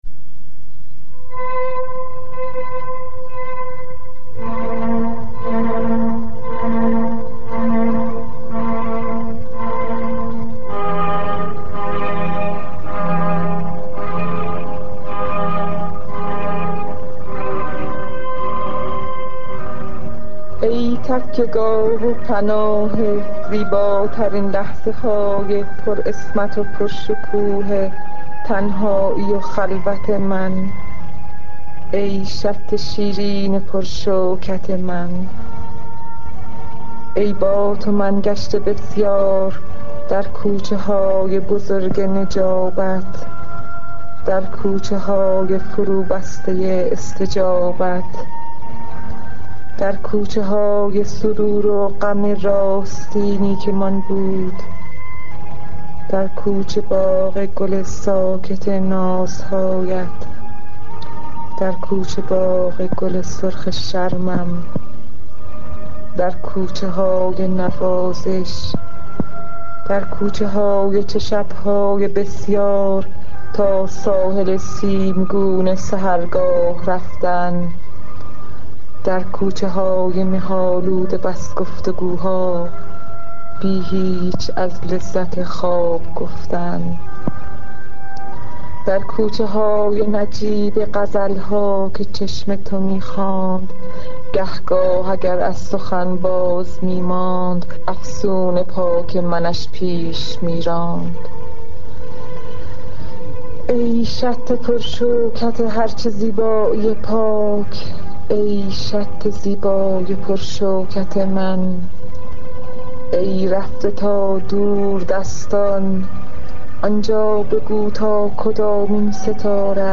دریافت فایلدانلود شعر با صدای فروغ فرخزاد